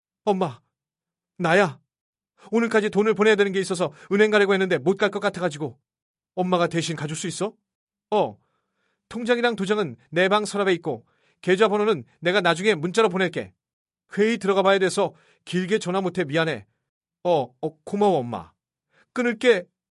들려드린 목소리는 모두 AI로 복제된